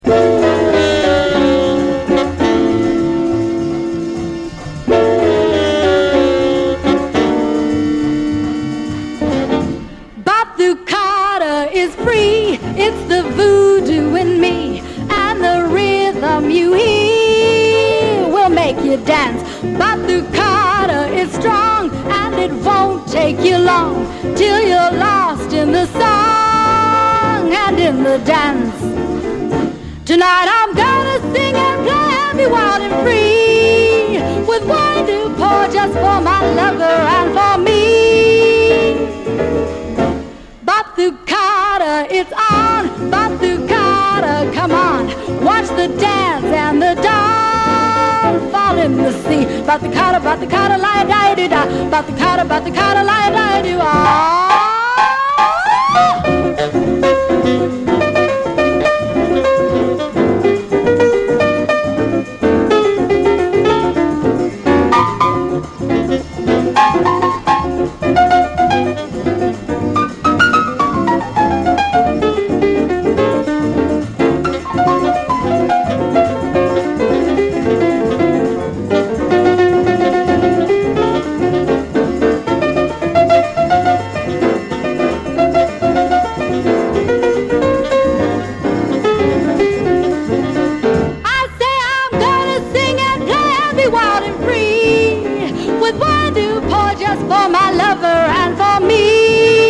Uber rare latin jazz album
1st edition stereo brown label.